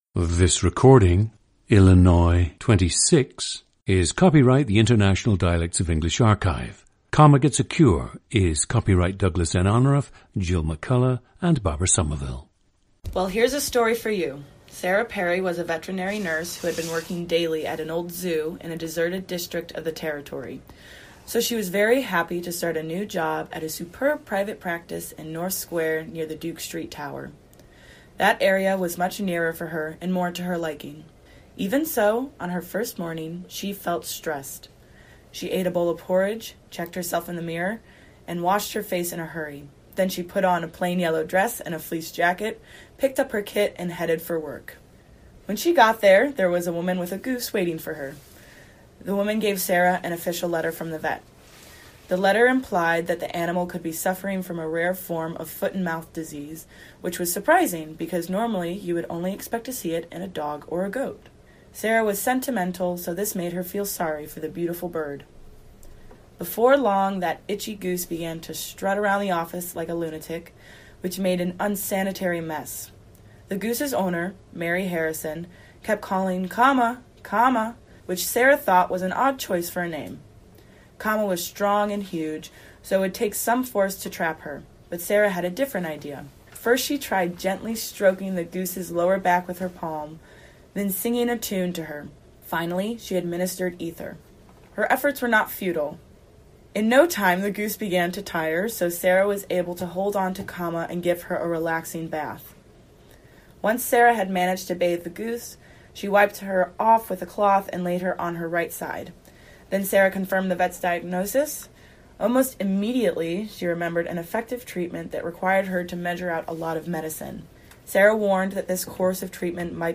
GENDER: female
Both parents are from rural Kansas.
The speaker was encouraged to speak in her “home accent” for this recording. It is possible that her study of the General American dialect while in college, in addition to her theatre studies, might have modified her dialect somewhat.
• Recordings of accent/dialect speakers from the region you select.